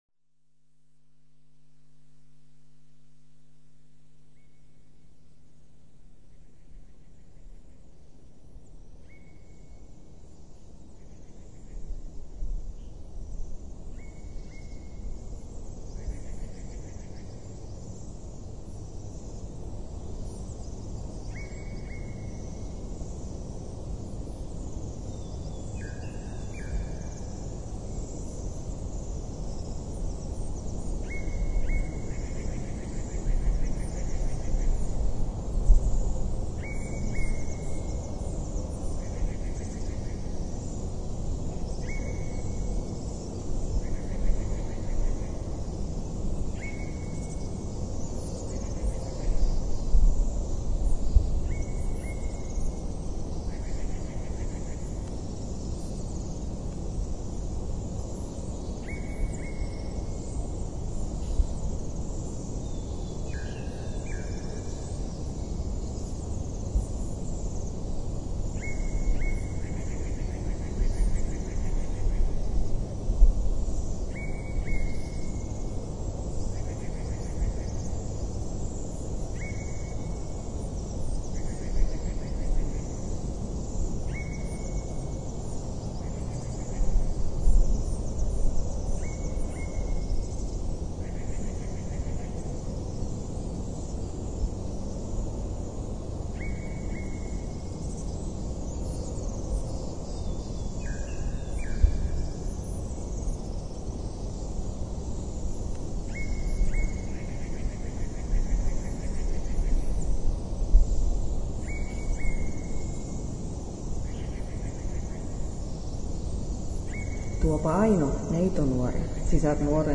Finnish lullabys sung